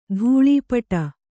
♪ dhūḷī paṭa